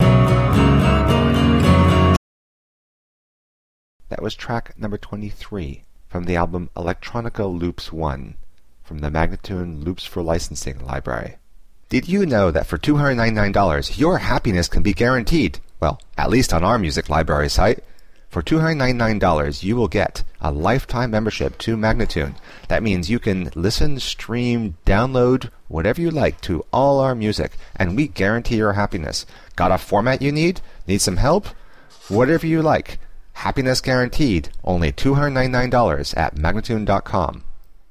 106-FNo-beatundercontrol-1043